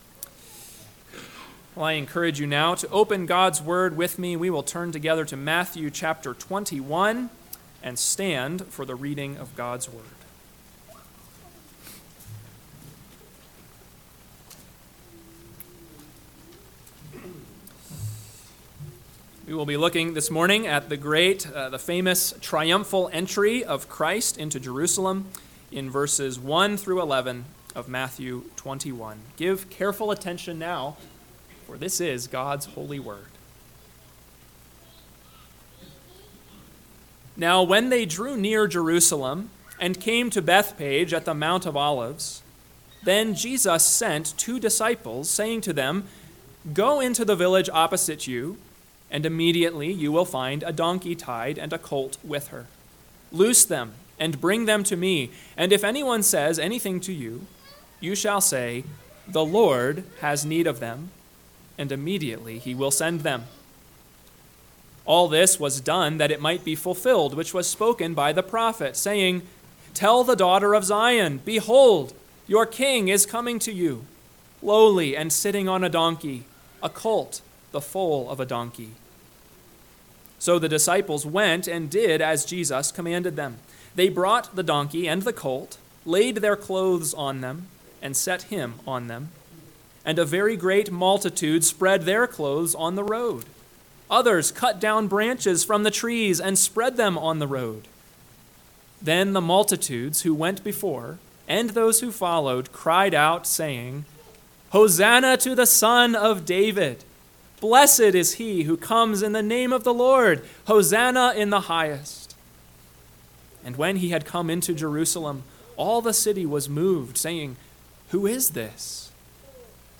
AM Sermon – 8/18/2024 – Matthew 21:1-11 – Northwoods Sermons